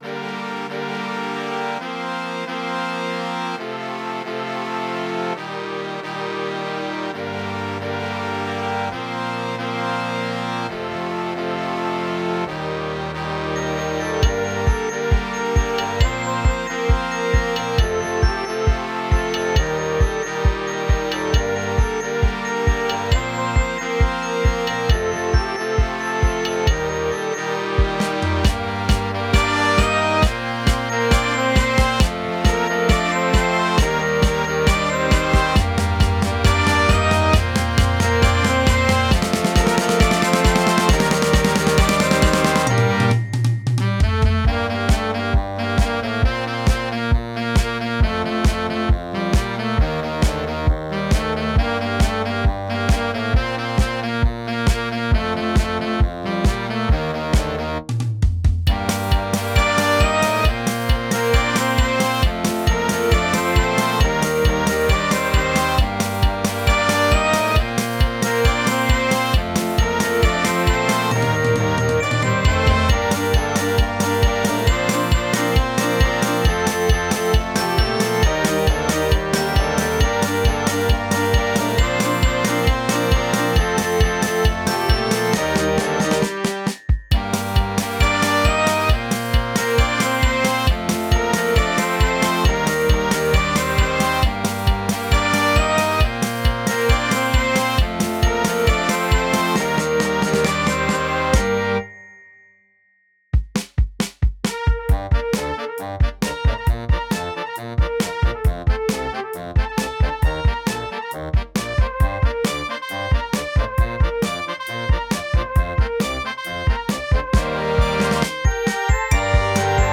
Song – Jugendkapellen-Fassung zum Anhören: